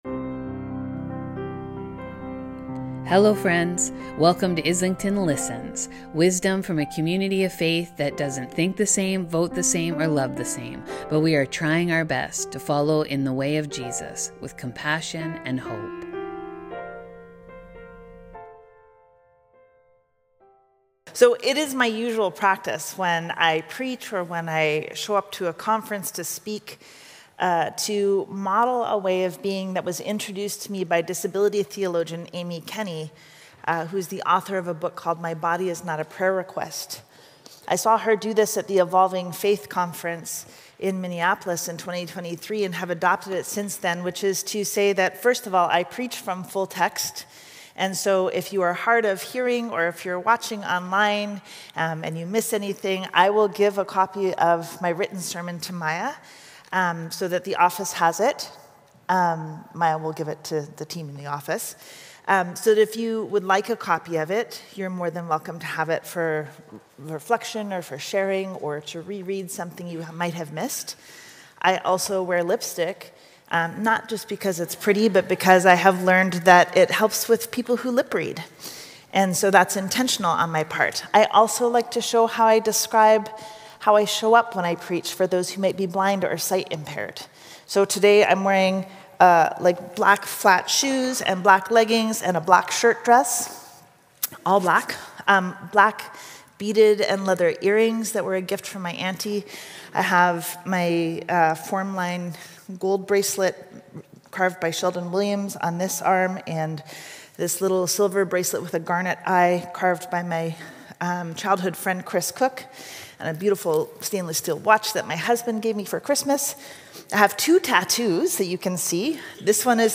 On Sunday, March 1, The Very Rev. Carmen Lansdowne continued our theme for the season "Wild & Holy" by teaching us how to reclaim the world repentance as a tool for transformation.